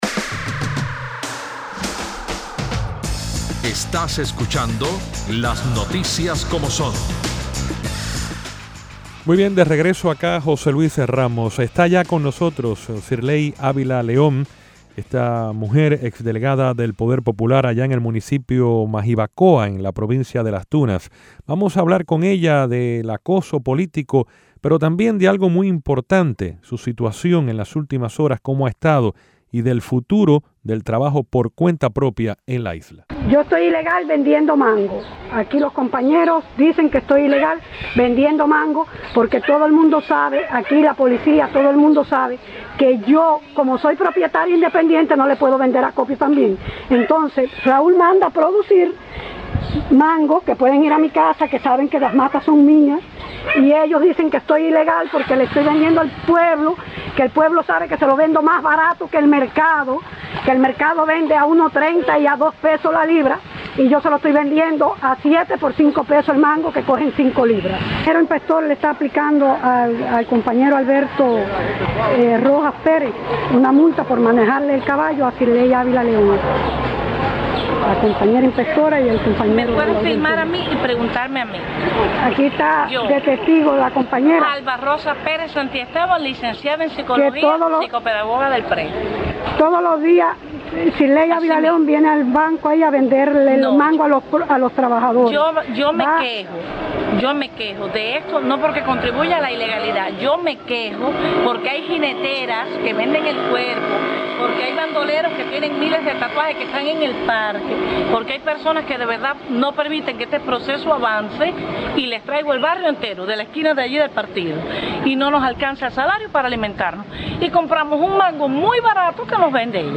Hoy en Las Noticias Como Son, conversamos con Sirley Avila León, ex delegada del Poder Popular en Majibacoa, Las Tunas. Entre los temas, el acoso político y el futuro del trabajo por cuenta propia en Cuba.
Finalmente, conversamos con el arzobispo de Santiago de Cuba, Dionisio García, sobre el trabajo que realiza la Orden de Malta, y la ayuda que está dando en la reconstrucción de las casas dañadas por el huracán Sandy el pasado año.